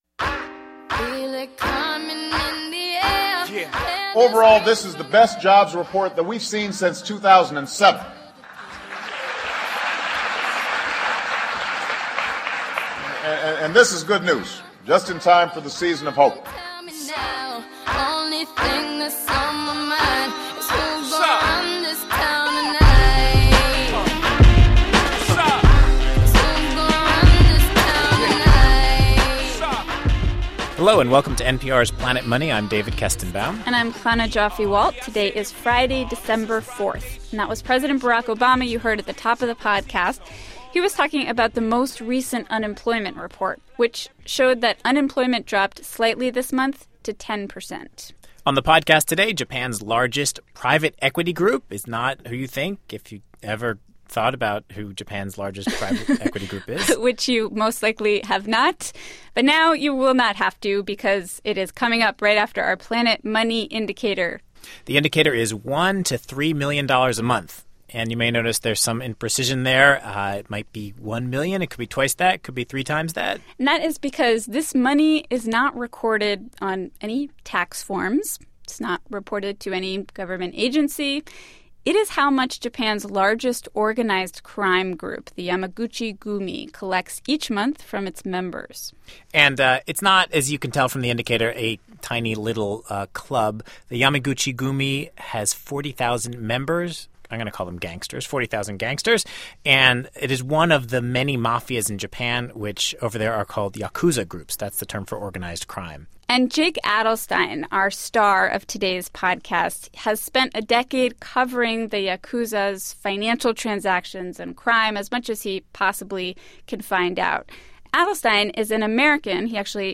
An American journalist in Japan, Jake Adelstein, has spent a decade covering the world of organized crime. He talks about how the business of the yakuza groups has changed over time and how tighter government restrictions have pushed the Japanese mob into more "traditional" investments.